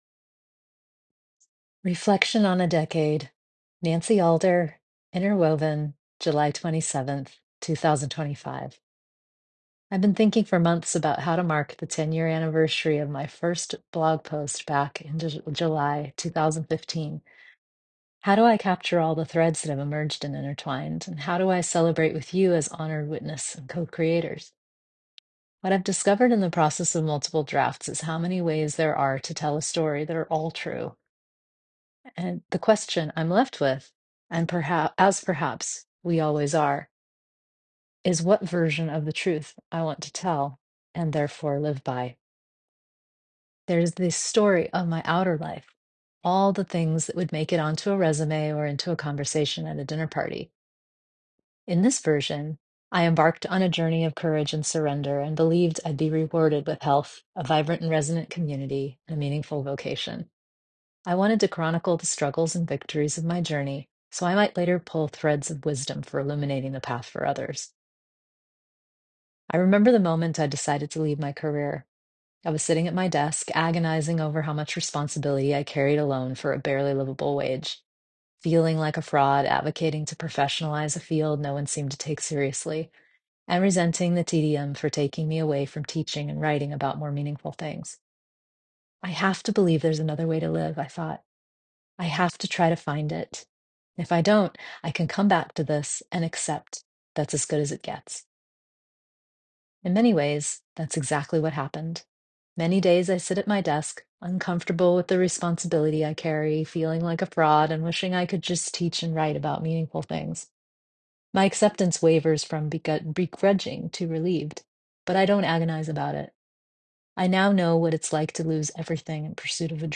Enjoy this 10-minute post – with links to stand-out posts over the past decaded – or click the title to let me read it to you via the link at the top of the webpage.